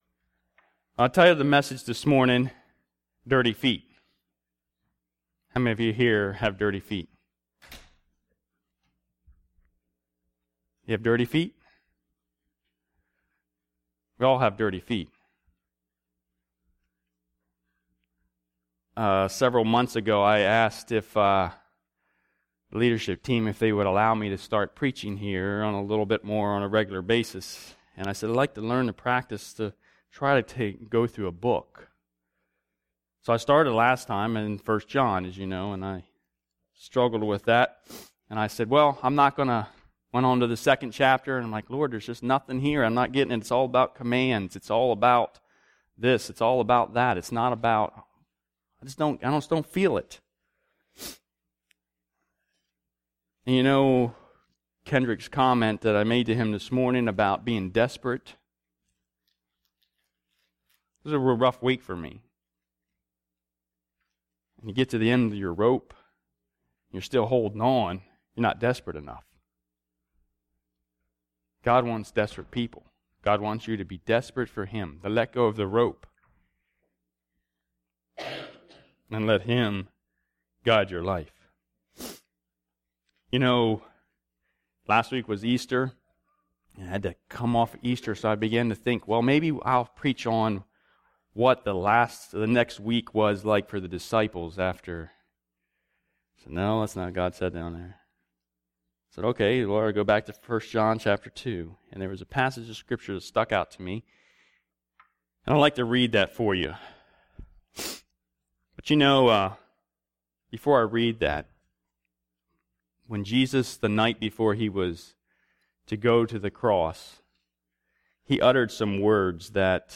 Maranatha Fellowship's Sunday Morning sermon recordings.